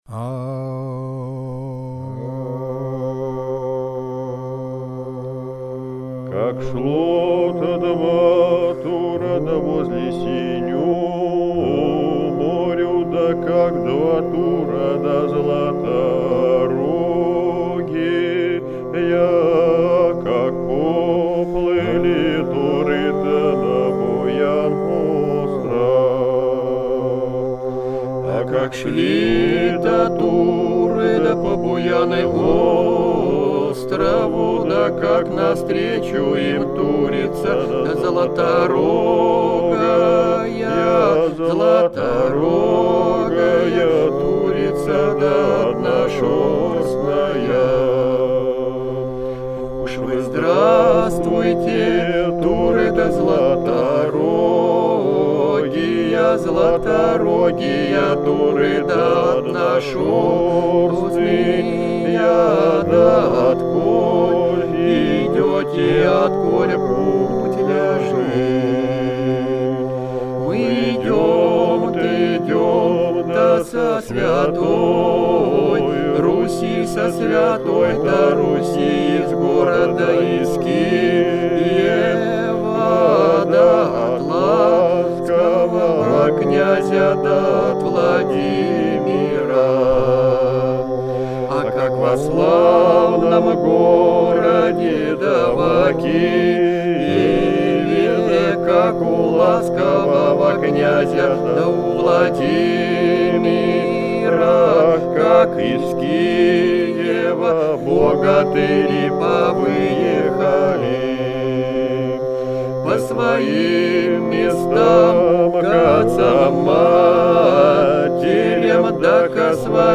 Былина